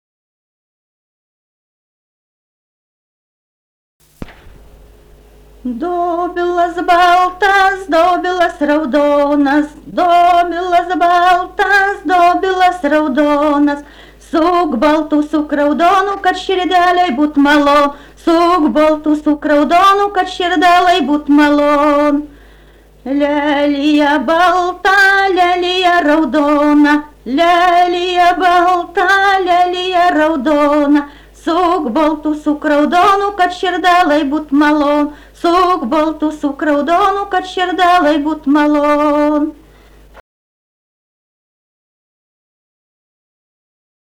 daina
Ryžiškė
vokalinis